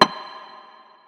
Perc 10 [ revved ].wav